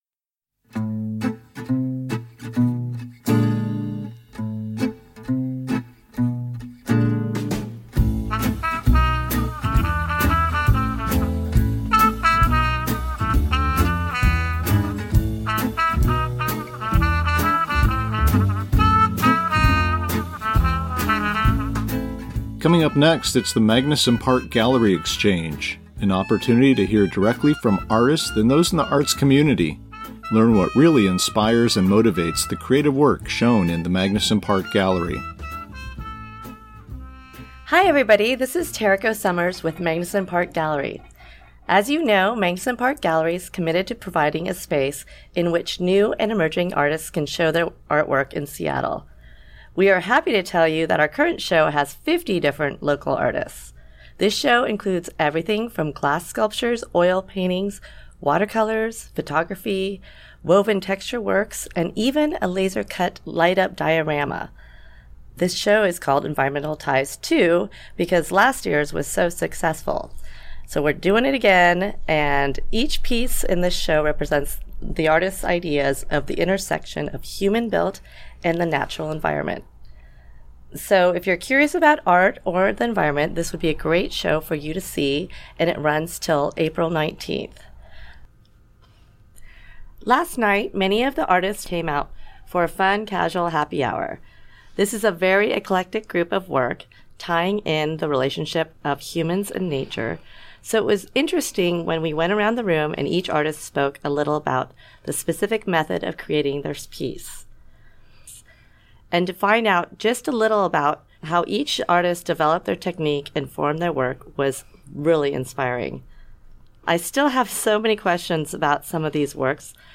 Small Works: A Conversation